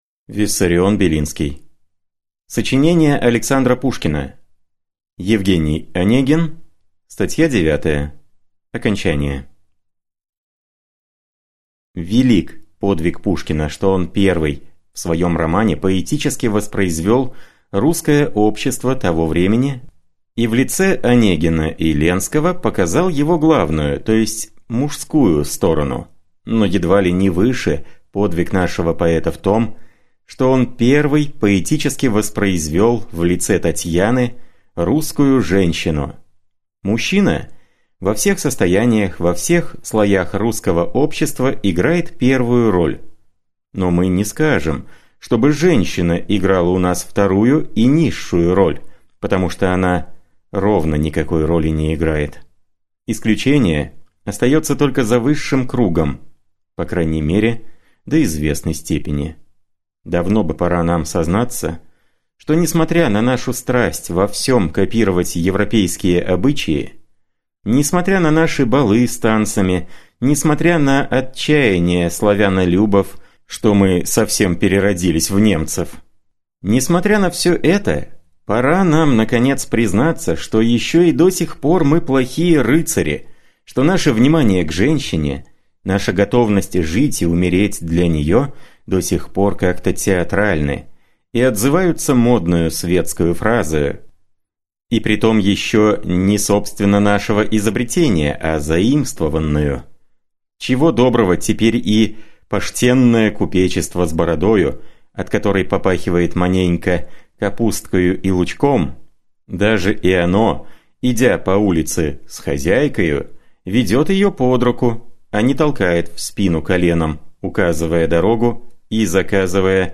Аудиокнига Сочинения Александра Пушкина: «Евгений Онегин». Статья девятая | Библиотека аудиокниг